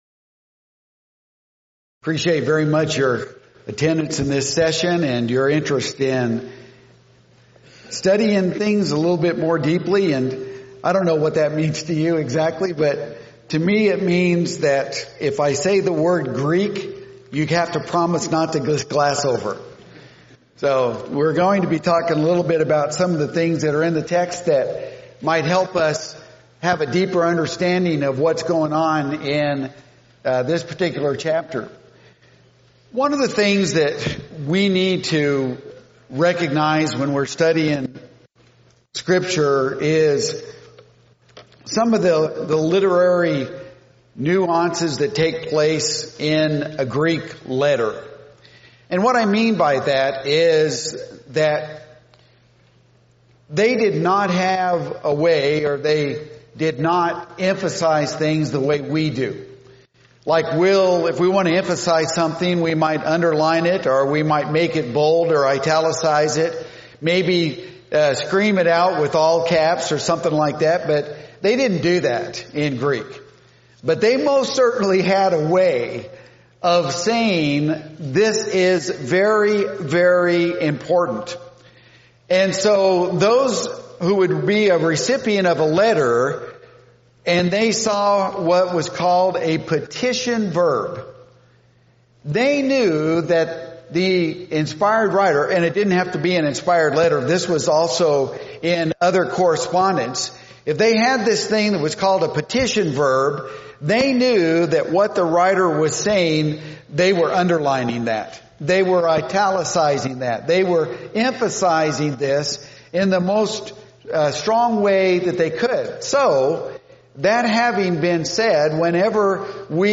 Event: 2018 Focal Point
Preacher's Workshop